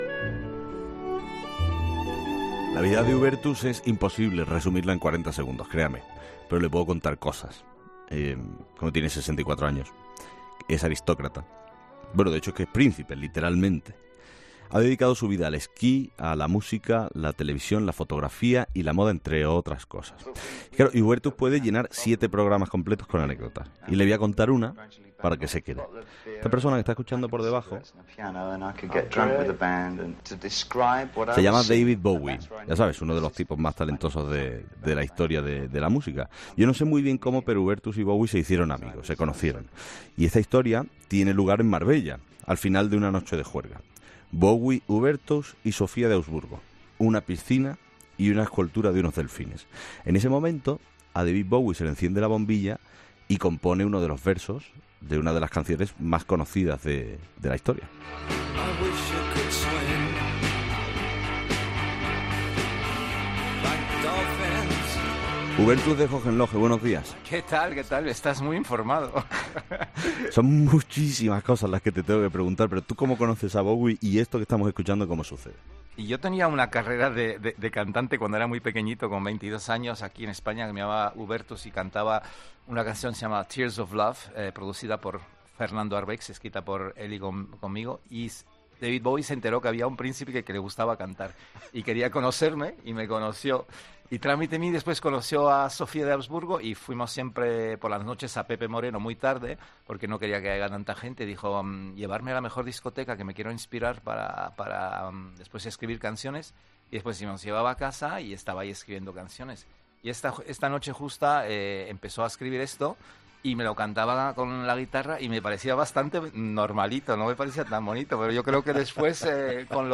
Escucha la entrevista completa a Hubertus de Hohenlohe, polifacético príncipe austríaco